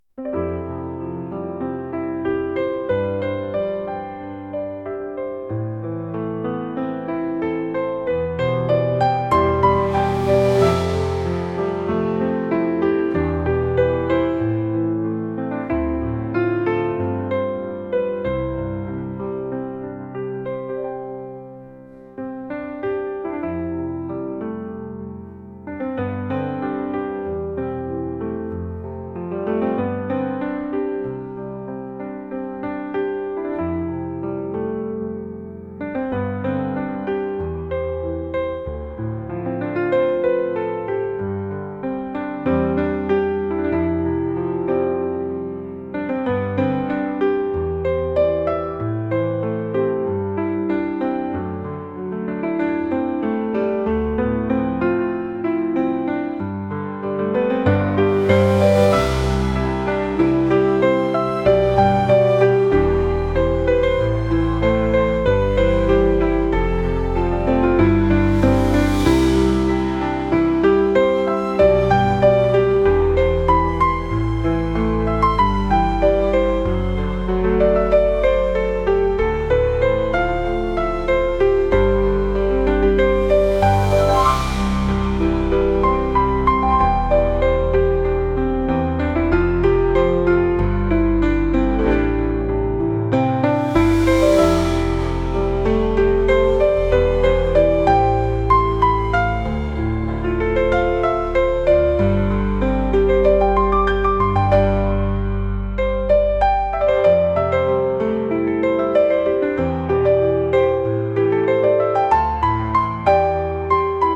思い出の場所とさよならを告げるようなピアノです。